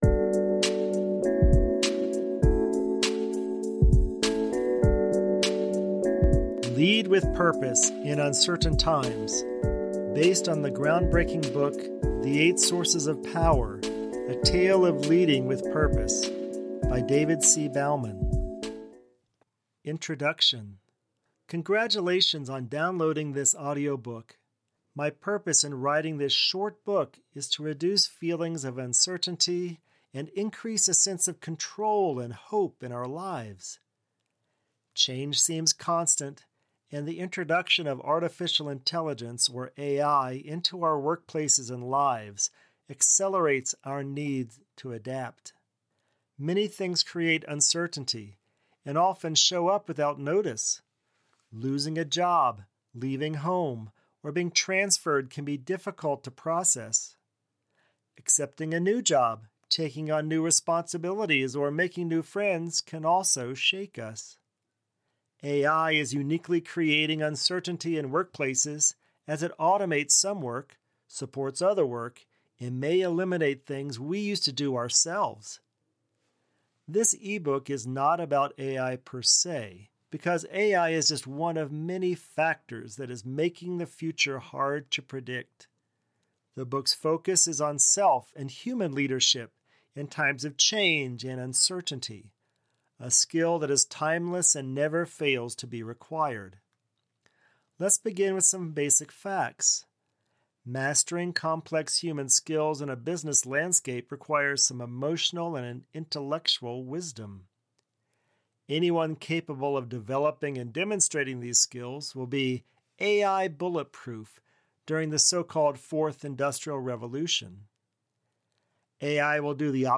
Audio-book - Lead with Purpose (mp3)